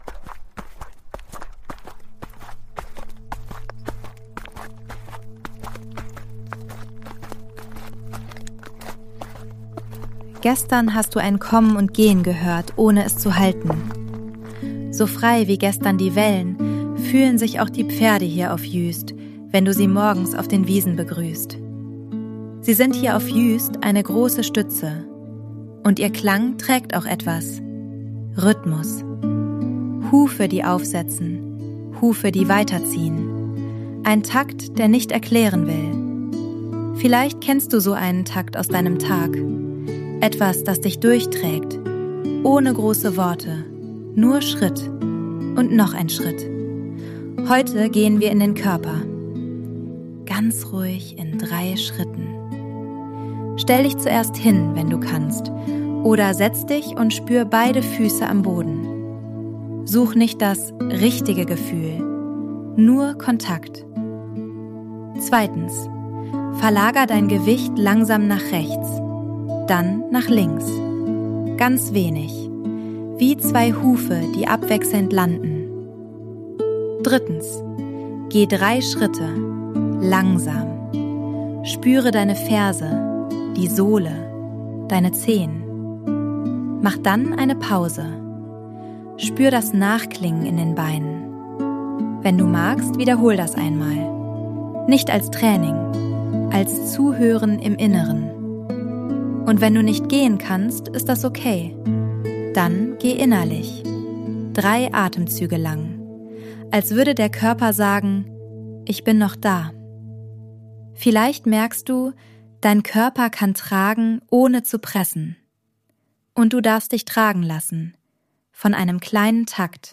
Hufklappern als Takt unter den Füßen.
Juist Sounds & Mix: ElevenLabs und eigene Atmos